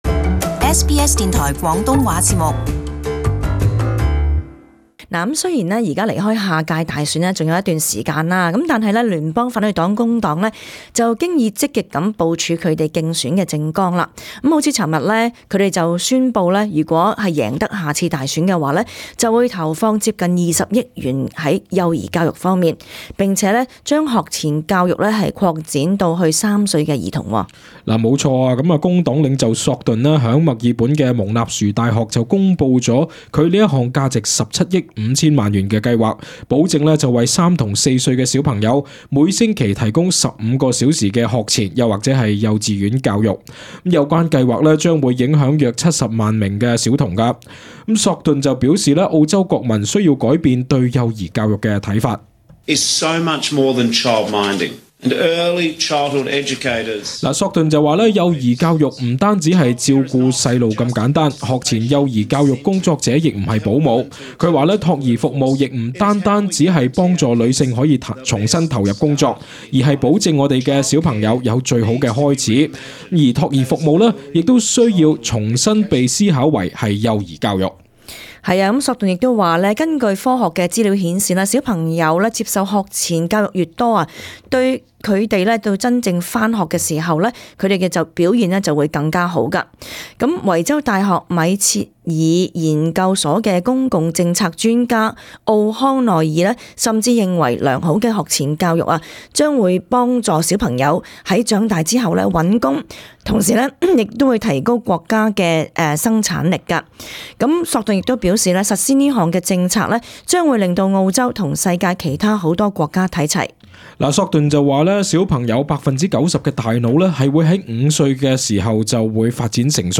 【時事報道】工黨宣布投放近20億於幼兒教育